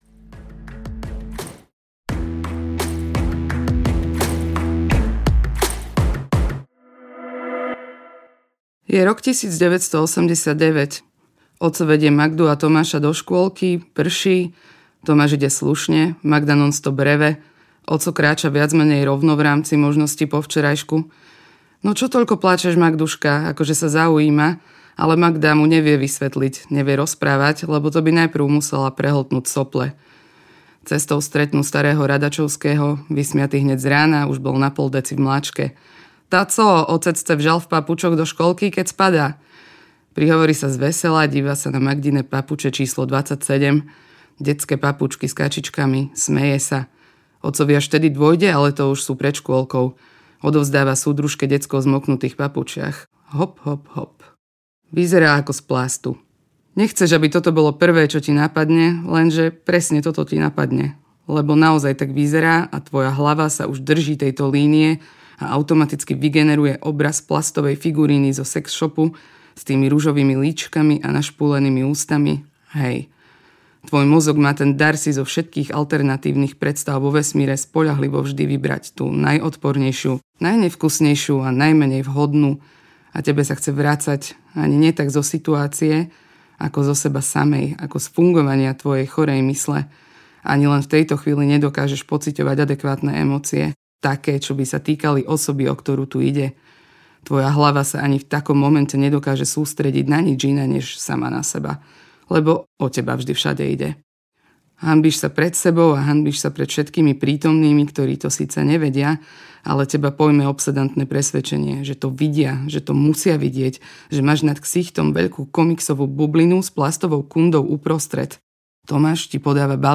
Babička© audiokniha
Ukázka z knihy